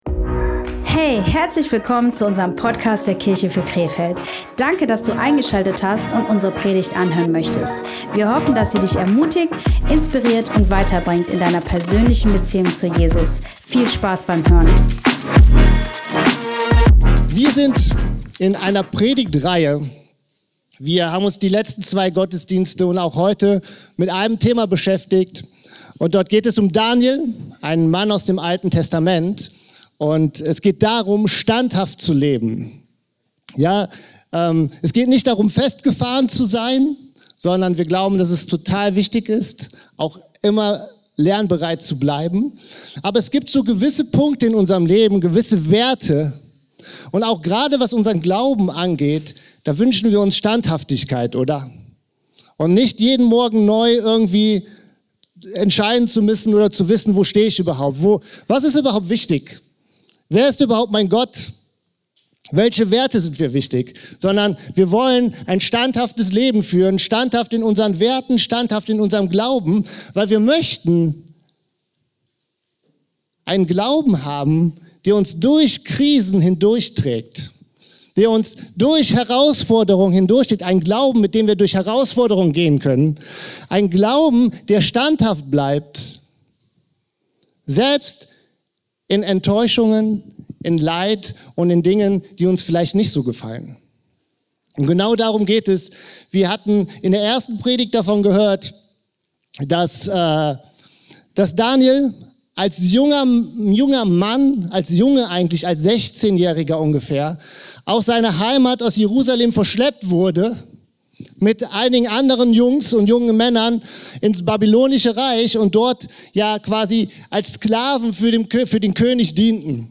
In dieser Predigt beschäftigen wir uns mit der Standhaftigkeit von Daniels Freunden. Im 3. Kapitel des Buches Daniel lesen wir, wie sie in ihrem Glauben standhaft bleiben und dass ihr Vertrauen auf Gott größer ist, als die Angst vor dem Tod.